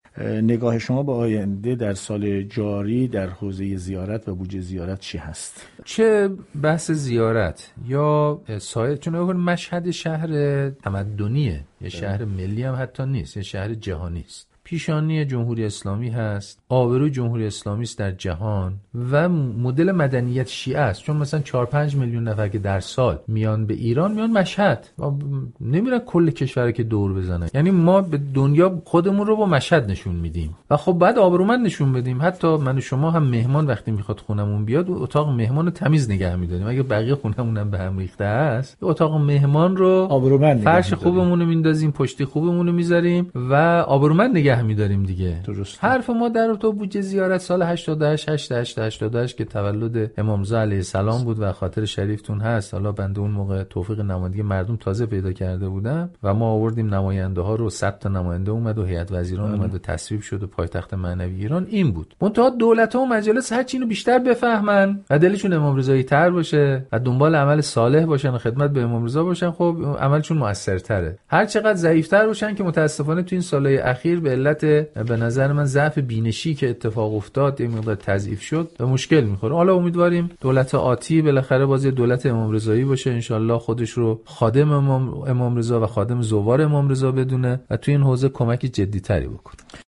به گزارش خبر رادیو زیارت ، دکتر سید امیر حسین قاضی زاده نایب رییس مجلس و نماینده مردم مشهد و کلات در خانه ملت در گفتگوی ویژه رادیو زیارت اعلام کرد : مشهد یک شهر تمدنی و یک شهر جهانی است در واقع پیشانی و آبروی جمهوری اسلامی در جهان و مدل مدنیت شیعه است.